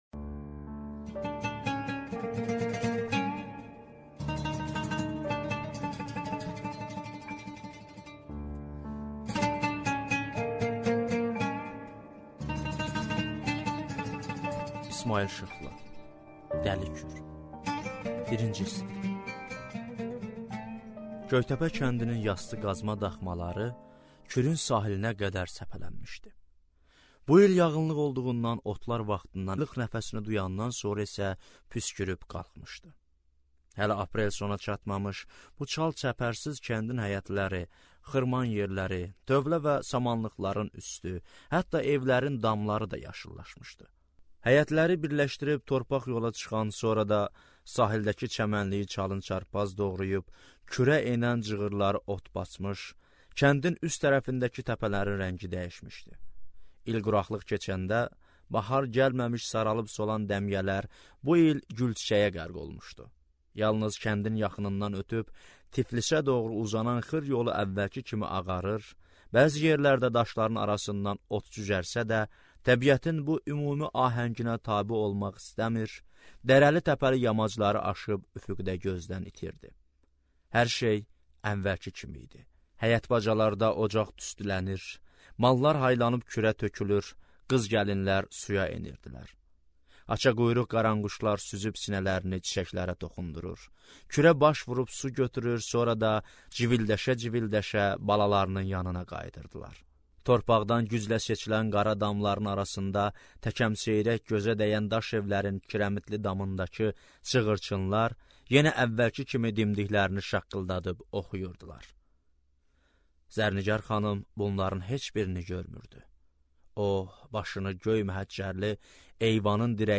Аудиокнига Dəli Kür | Библиотека аудиокниг